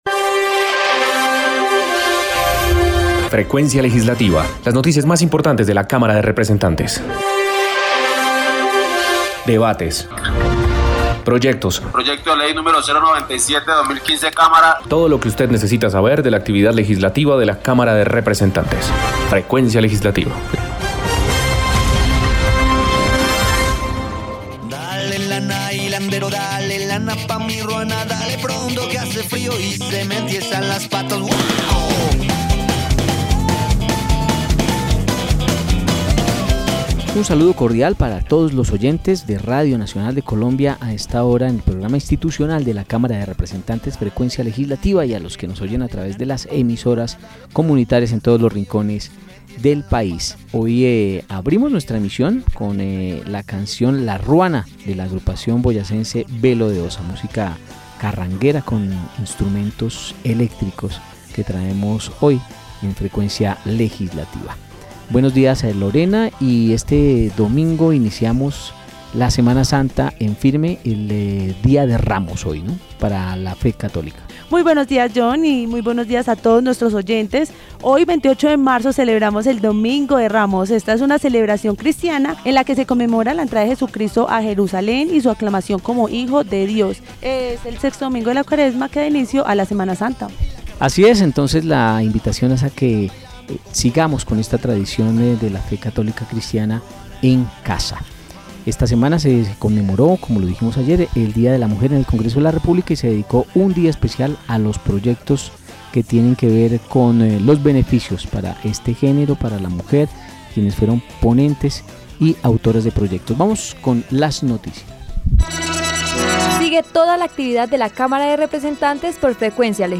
Programa Radial Frecuencia Legislativa 28 de Marzo de 2021